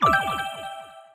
Free_Trigger_Free_Sound.mp3